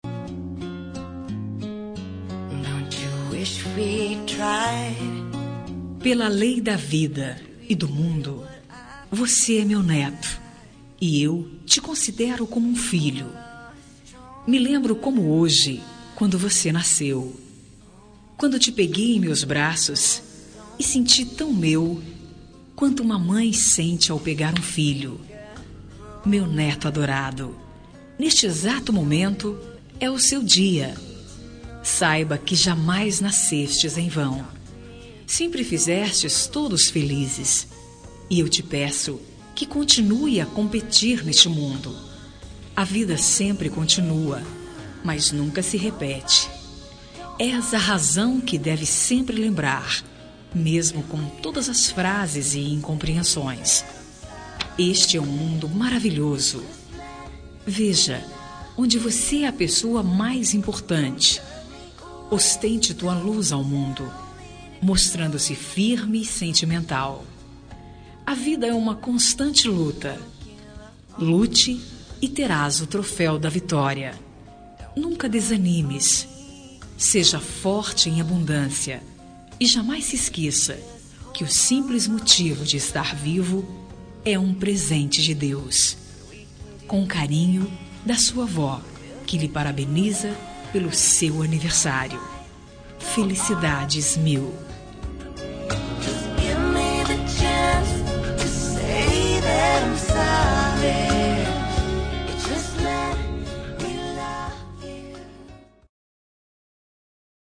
Aniversário de Neto – Voz Feminina – Cód: 131050